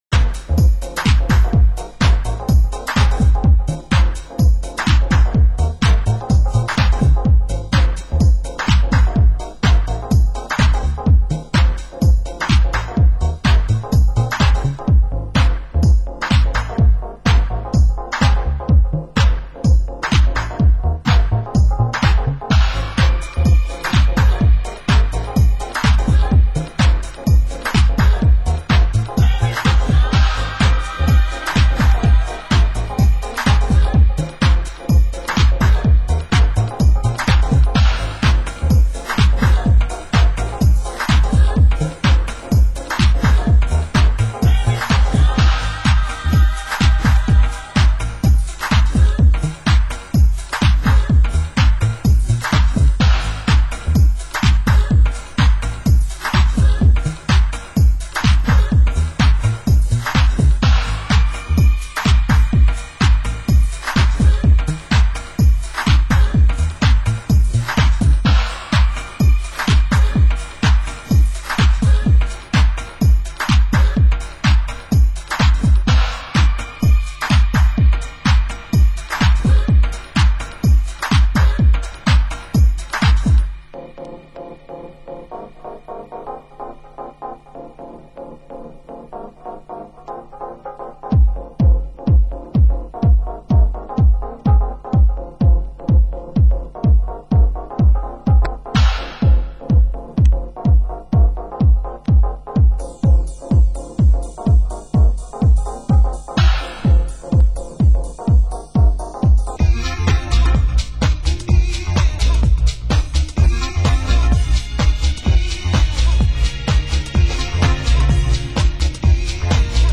Genre Deep House